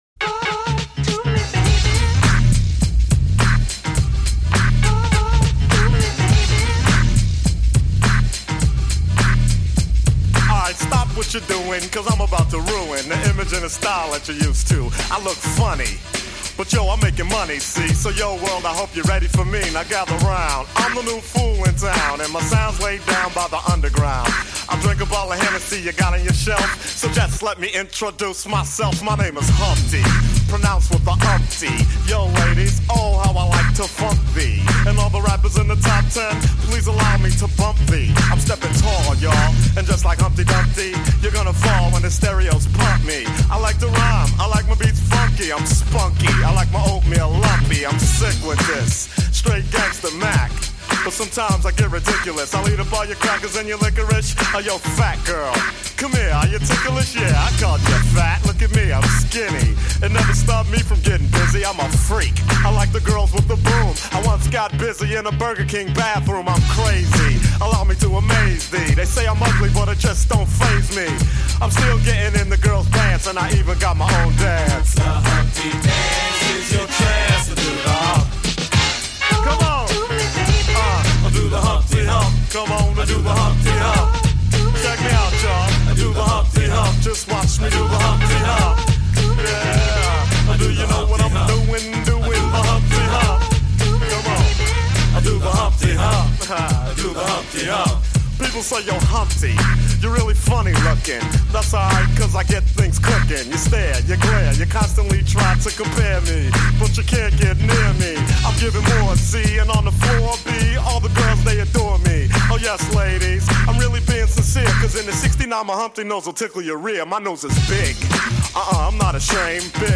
aanstekelijk hiphopfeest met swingende raps
laag dampende P-Funk
hiphop